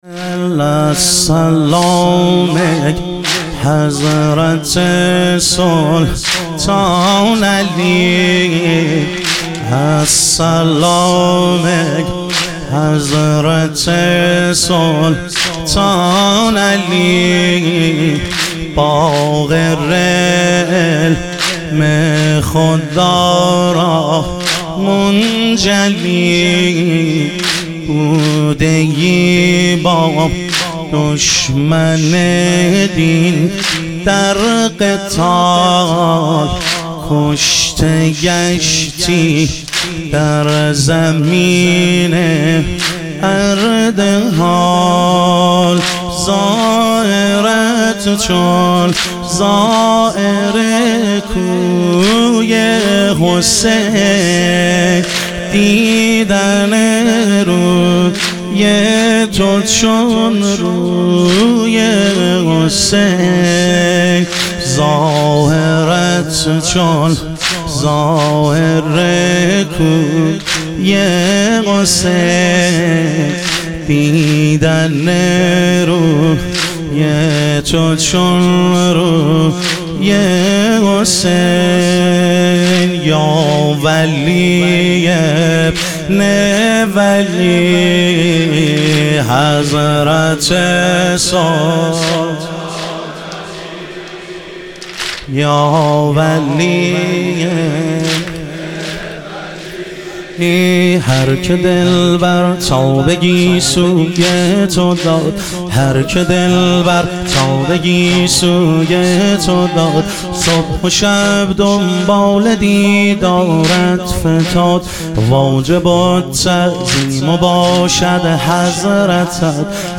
شهادت حضرت سلطانعلی علیه السلام - واحد